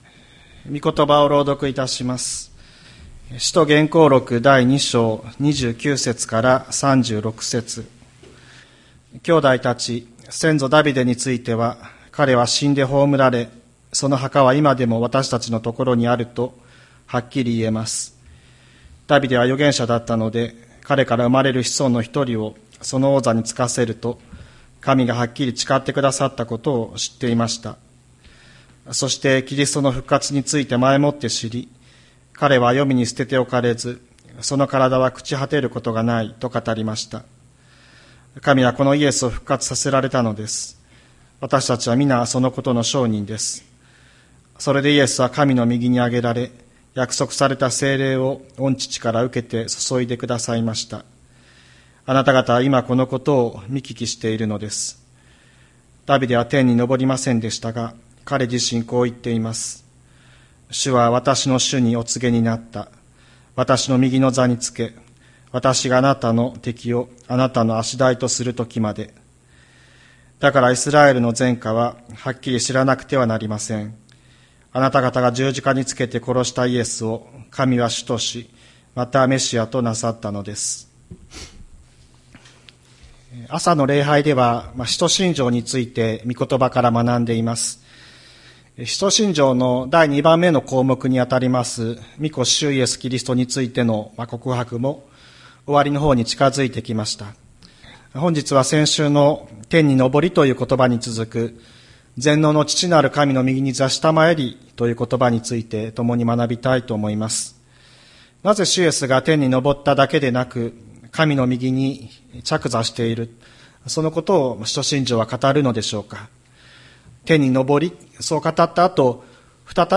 千里山教会 2024年05月05日の礼拝メッセージ。